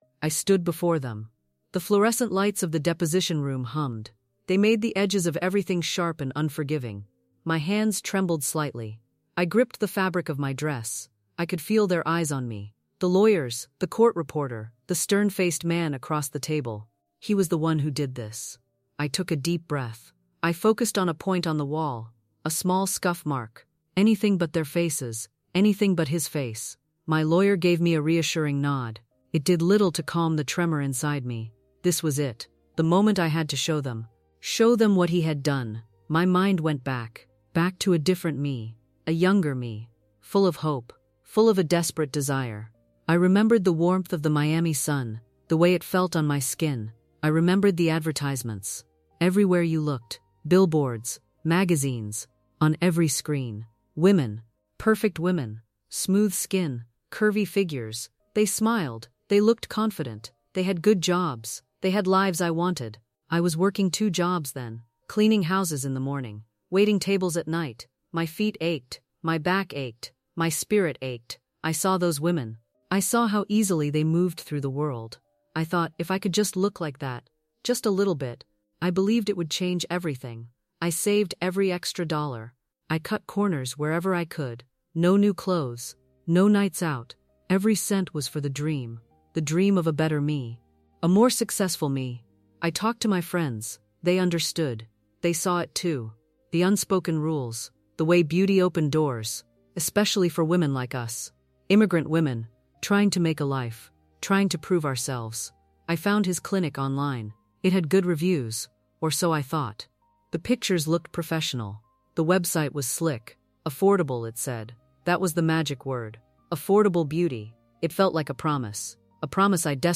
This script is optimized for TTS (Text-to-Speech) narration, focusing on a grounded, natural voice that avoids flowery metaphors in favor of visceral, lived experience.